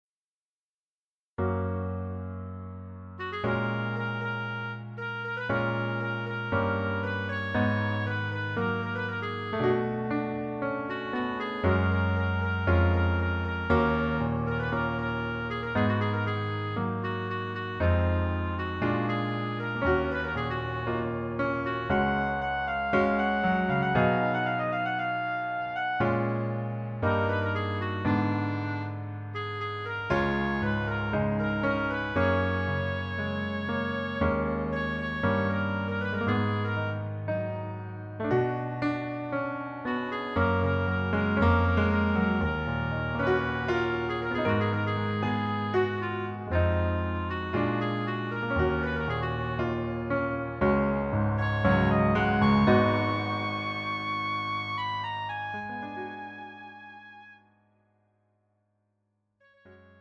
장르 가요 구분 Pro MR